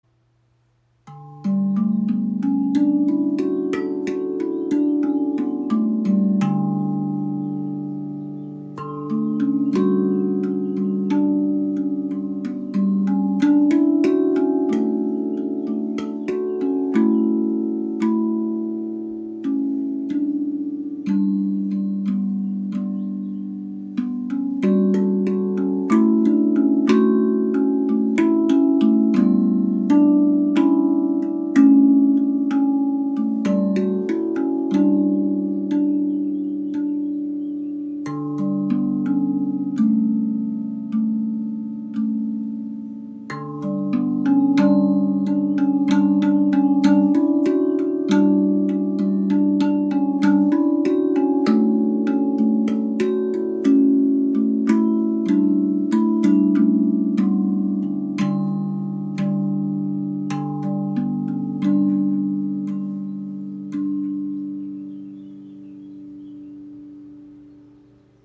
Erlebe die ARTQUINT Hybrid Drum – eine harmonische Verbindung aus Handpan und Zungentrommel. Warmer Klang, edles Design, verschiedene Stimmungen.
Klangbeispiel
Die futuristische Mischung zwischen Handpan und Zungentrommel – warm, weich und meditativ Stimmung D Dur mit 9 Tönen: D3 - G3 A3 B3 C#4 D4 E4 F#4 A4 Sanfter, lang nachschwingender Klang – vergleichbar mit einer Handpan D-Dur – Klare, erhellende Töne, die Energie und Fröhlichkeit bringen.